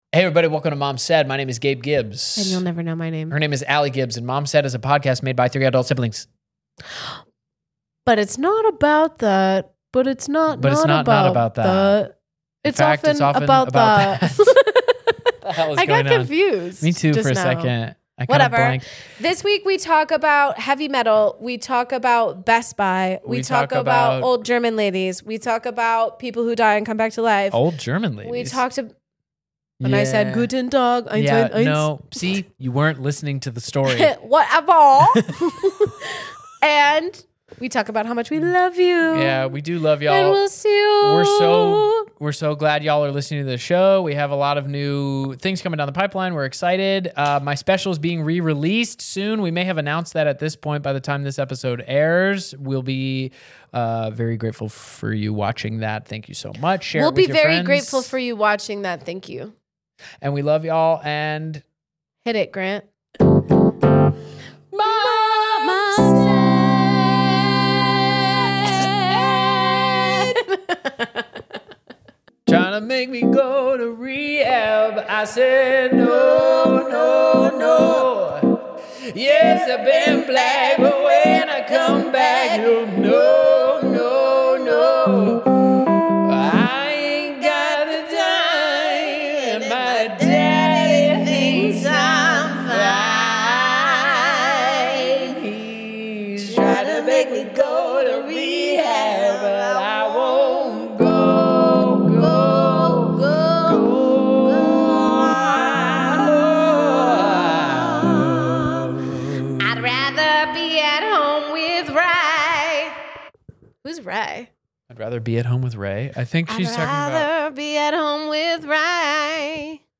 The week the siblings talk Best Buy, quirky uber rides, and a stuntman’s brief death.